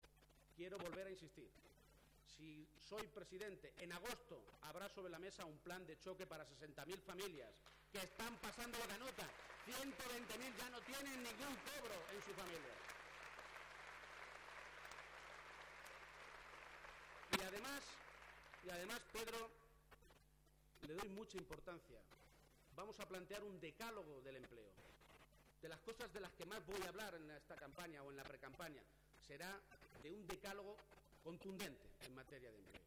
García-Page hacía esta mañana en el Teatro Auditorio de Cuenca la presentación de su candidatura.